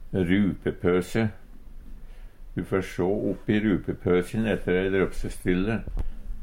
rupepøse sekk, ein laus pøse som va knytt fast på ein bakmeis Eintal ubunde Eintal bunde Fleirtal ubunde Fleirtal bunde ein rupepøse rupepøsin rupepøsø rupepøsøn Eksempel på bruk Du fær sjå oppi rupepøsin ette ei drøpsstrylle. Høyr på uttala Ordklasse: Substantiv hankjønn Kategori: Personleg utstyr, klede, sko Attende til søk